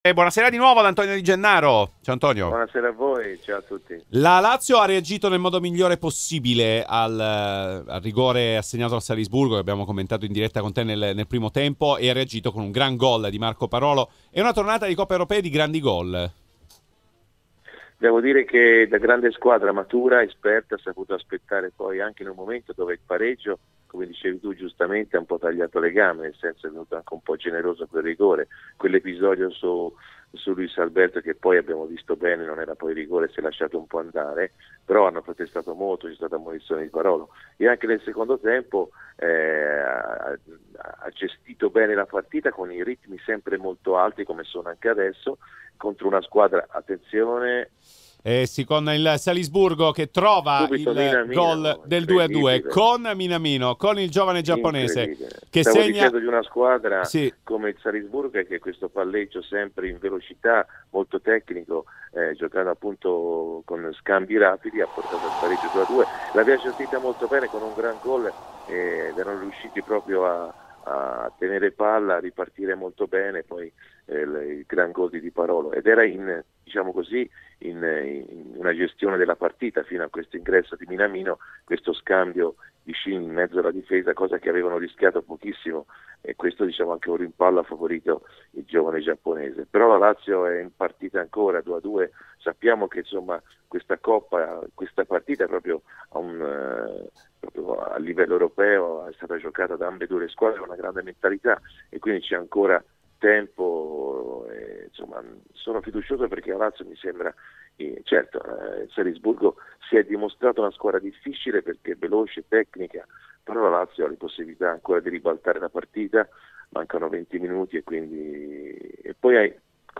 Antonio Di Gennaro, opinionista RMC Sport, a commento di Lazio-Salisburgo.